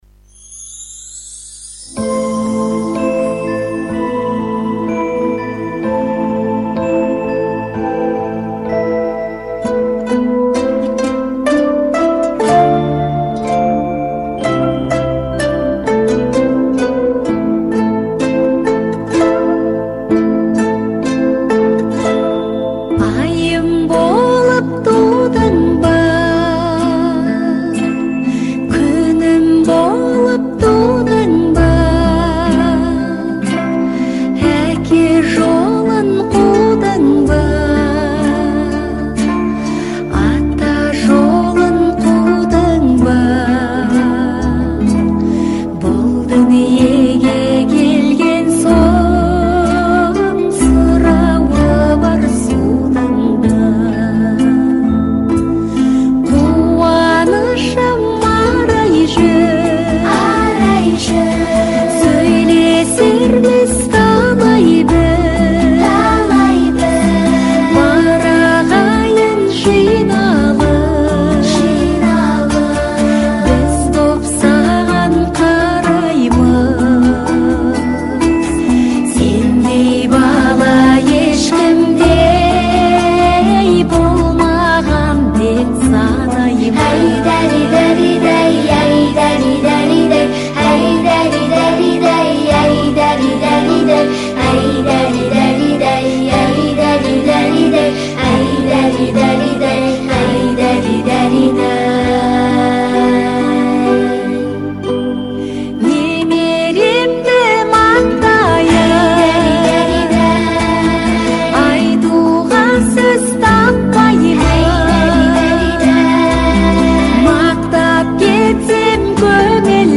Колыбельная на казахском 🇰🇿